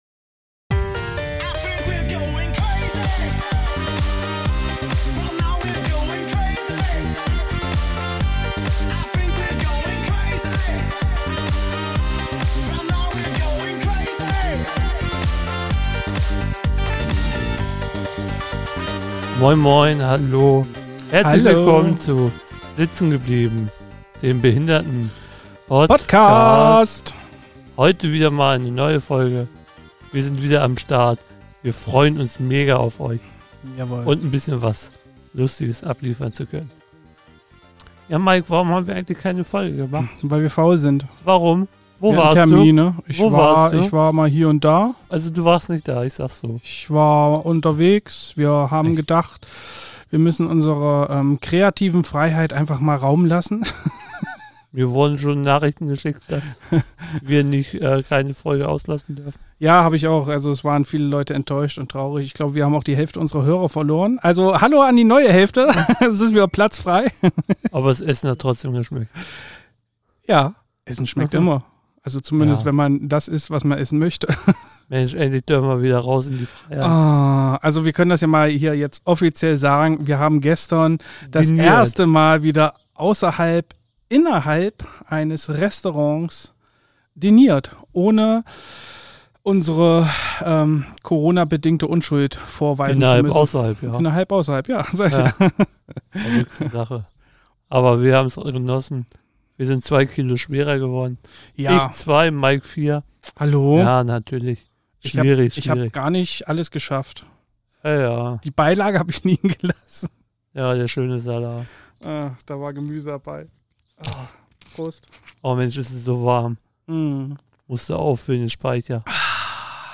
Das erste Mal seit Monaten auswärts essen, zwei neue Alben hören und das Debakel beim ESC verdrängen. Ach ja, und das alles endlich wieder draußen in unserem Freiluft-Studio.